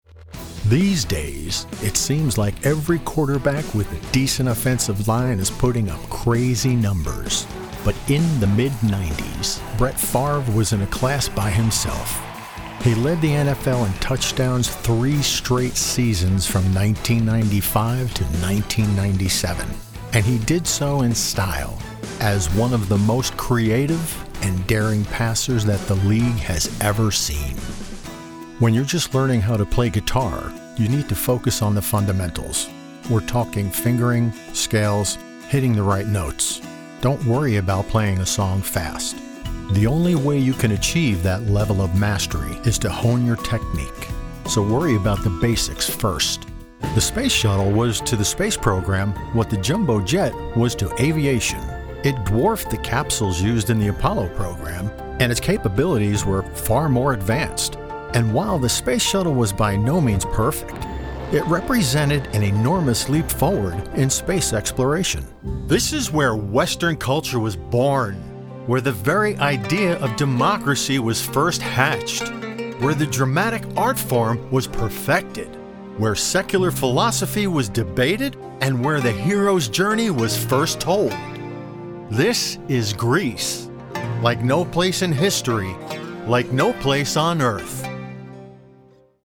Professional Voice Talent With 5 years experience.
Narration Demo
North Eastern US NJ, NY, Mass.